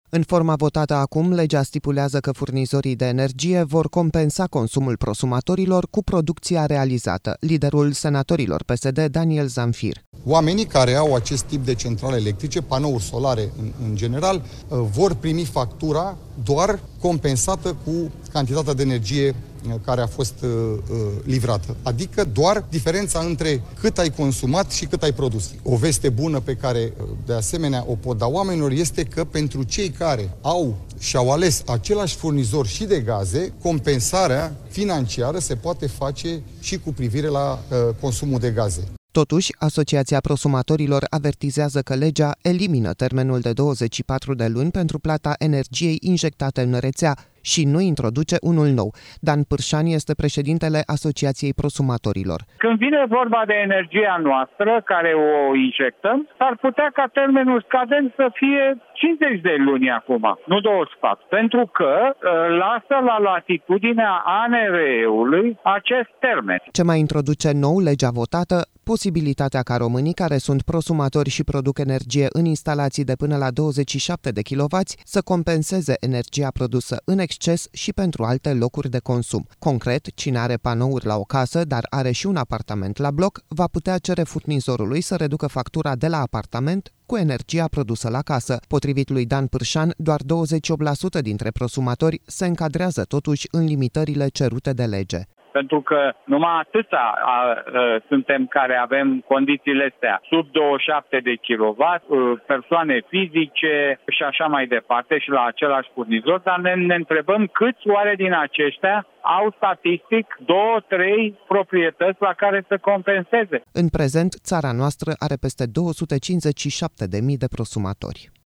Liderul senatorilor PSD, Daniel Zamfir: „Pentru cei care și-au ales același furnizor și de gaze, compensarea financiară se poate face și cu privire la consumul de gaze”